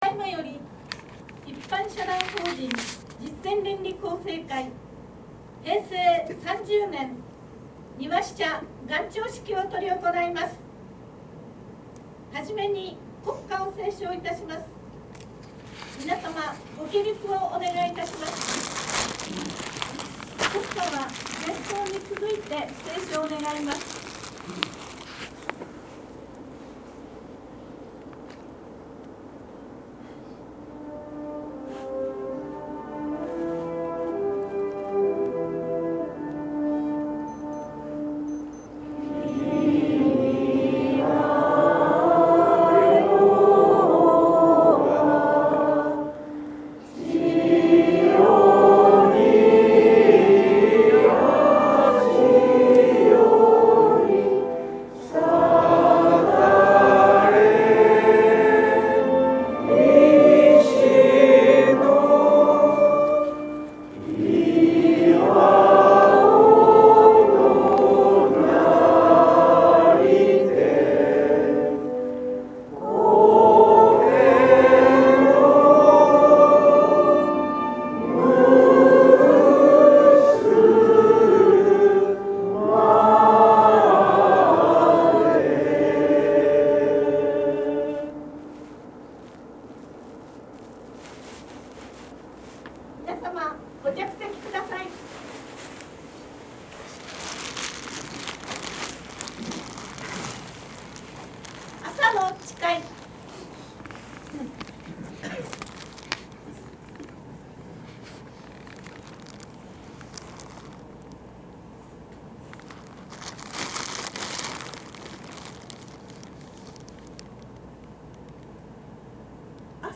平成30年元朝式
元朝式h30.mp3